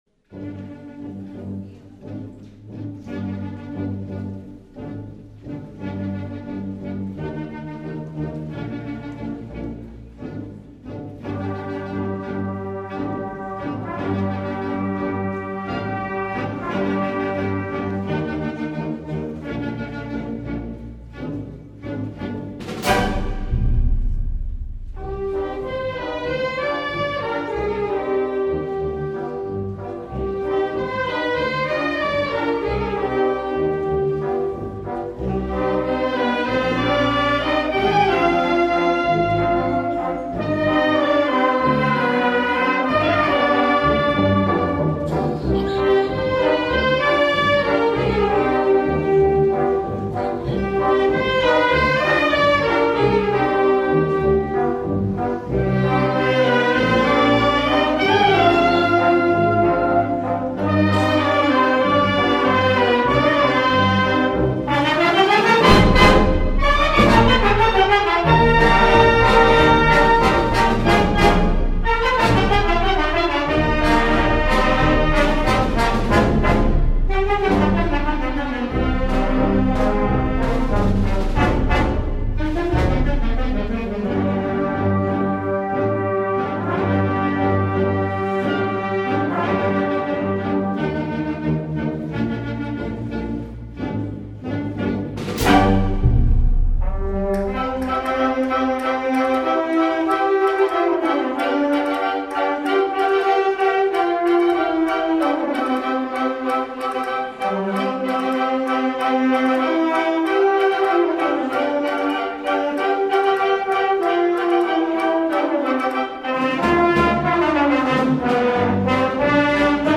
Archivo Sonoro - Resúmen - Concierto Navidad Banda de Musica